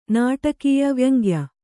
♪ nāṭakīya vyaŋgya